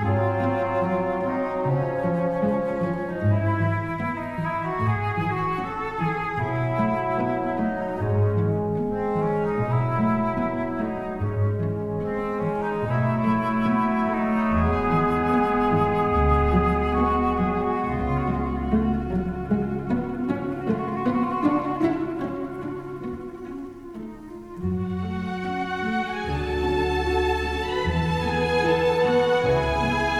approche zen-apaisante de la musique classique